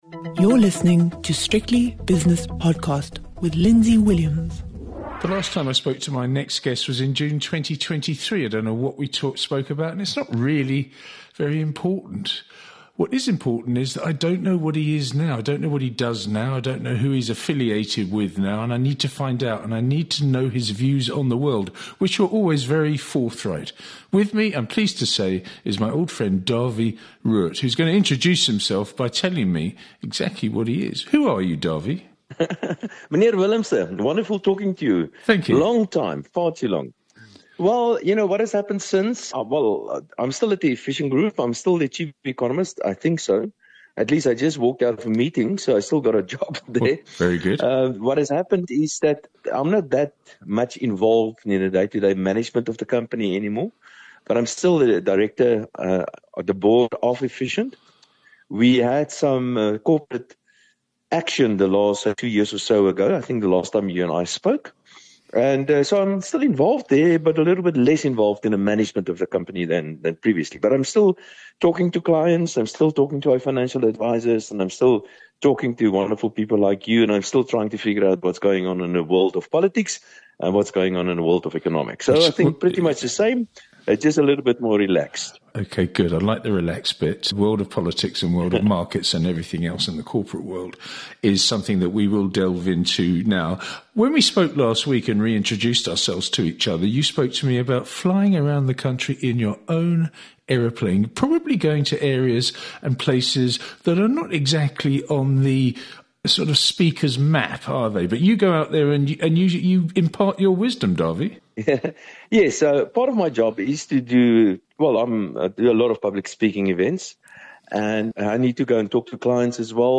Hosting the best of business and market leaders' interviews and analysis.